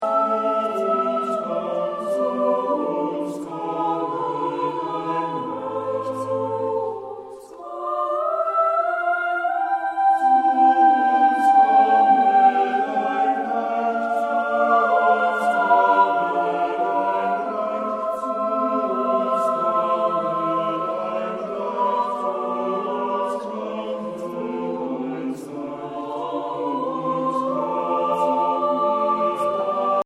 Eine Aufnahme überwiegend romantischer Chorliteratur
klangschönen und nuancenreichen Gesang